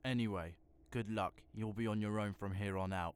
Voice Lines
Anyway good luck you_ll be on your own.wav